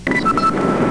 Sound Effects for Windows
doorbell.mp3